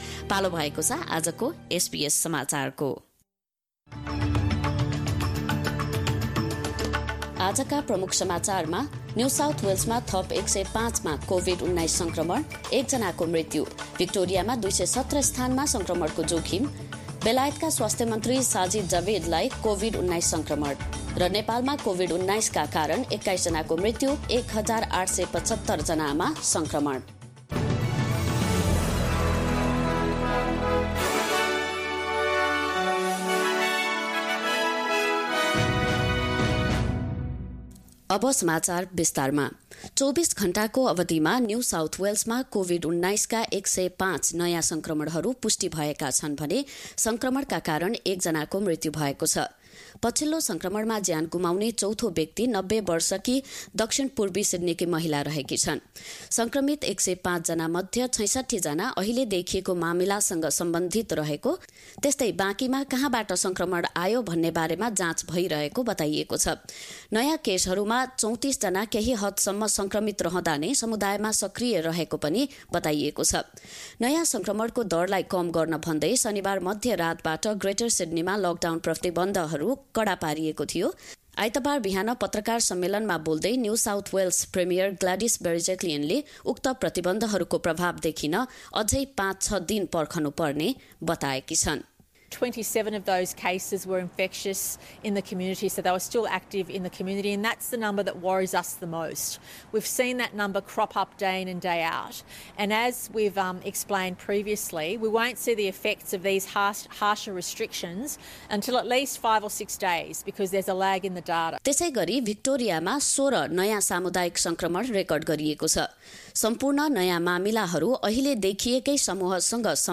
एसबीएस नेपाली अस्ट्रेलिया समाचार: आइतबार १८ जुलाई २०२१